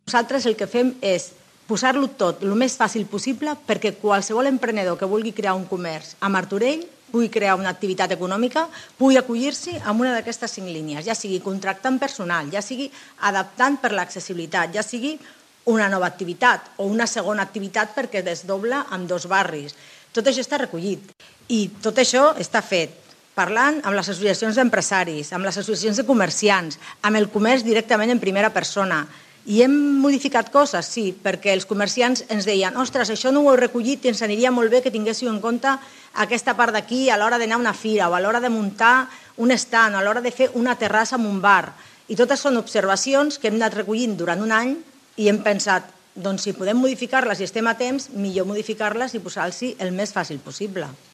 Rosa Cadenas, regidora de Promoció Econòmica de l'Ajuntament de Martorell